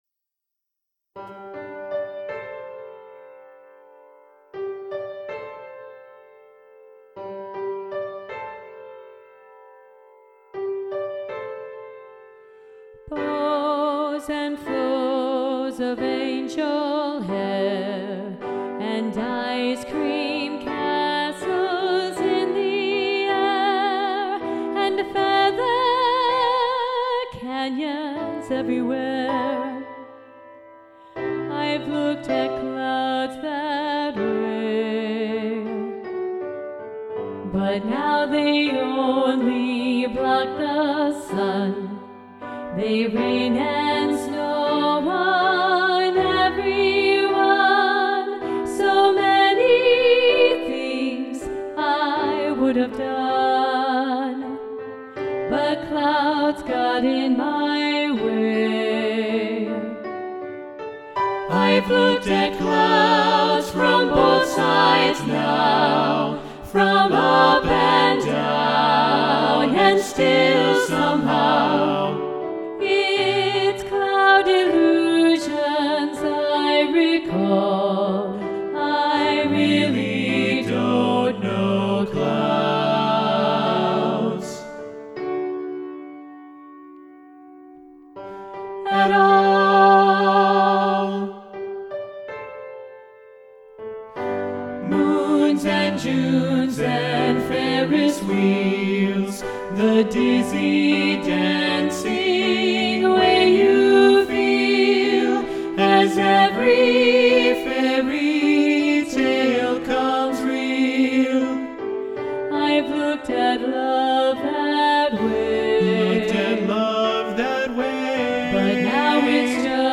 SATB Balanced Voices
Both-Sides-Now-SATB-Balanced-Voices-arr.-Roger-Emerson.mp3